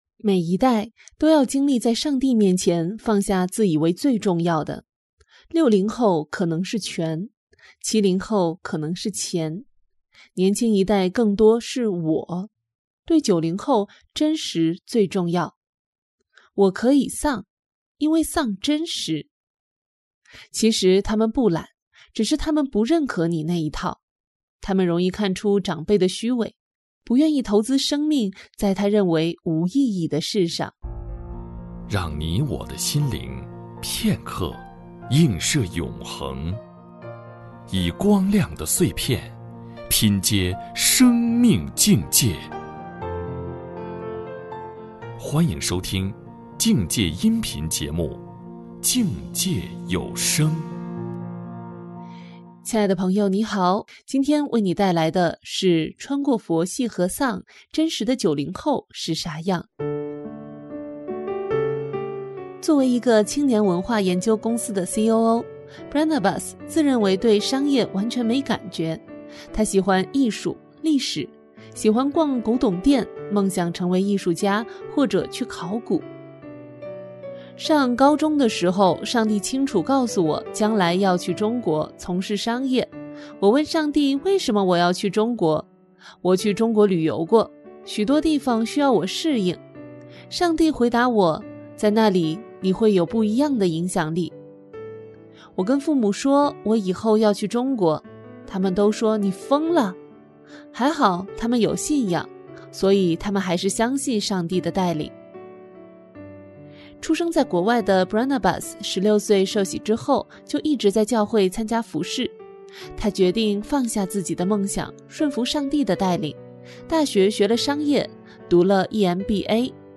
穿过佛系和丧，真实的90后是啥样？-这世代访谈（音频）.mp3